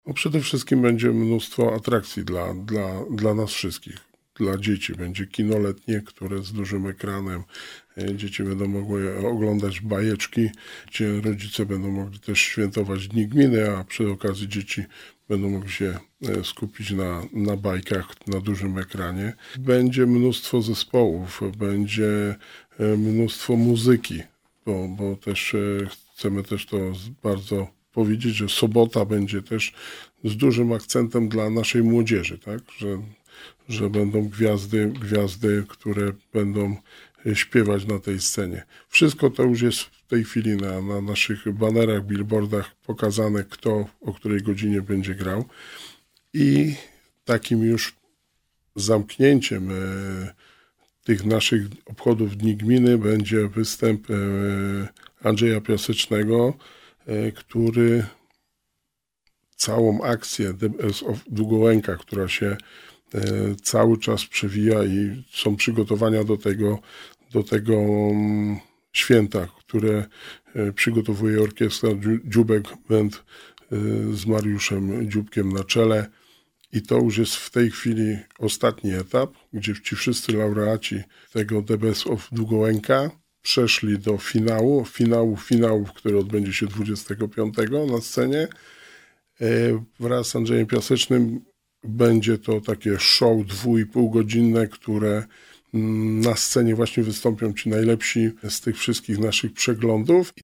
Mówi Wójt Gminy Długołęka Wojciech Błoński.